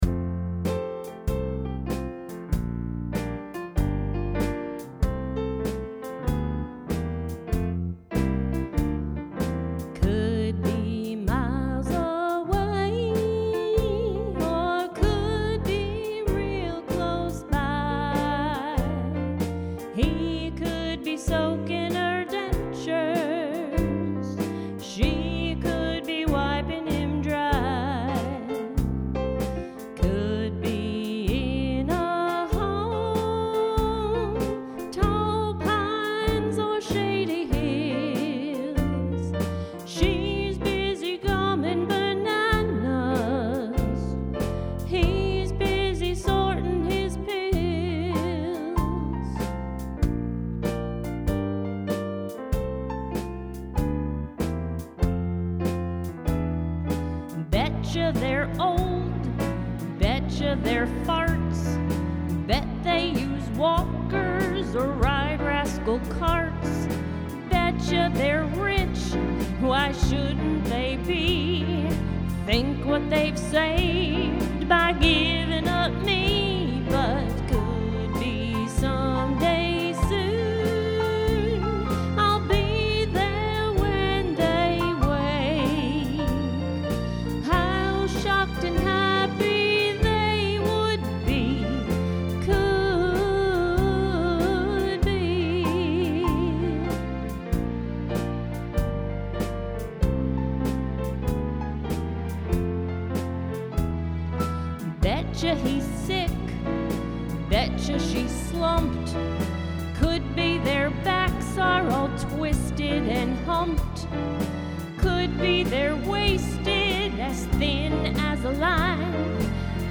Song Demos
(Music; Demo Song Production and Accompaniment)